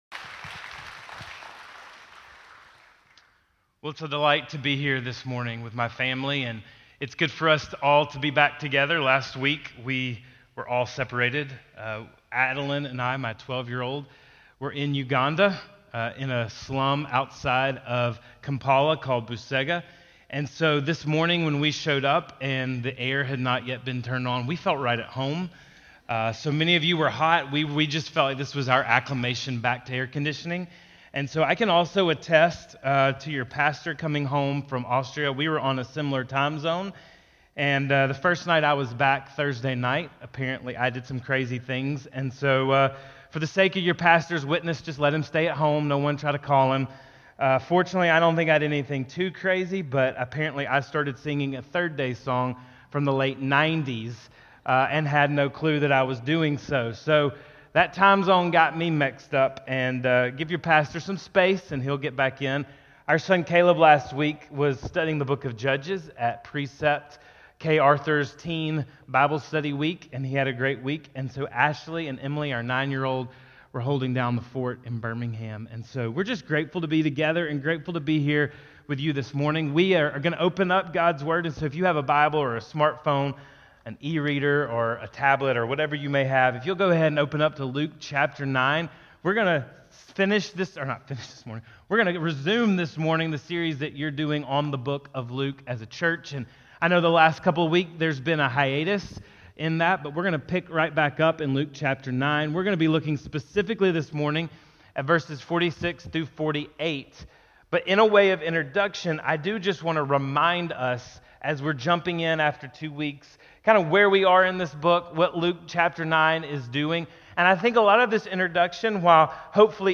Luke 9:46-48 at Northport Baptist Church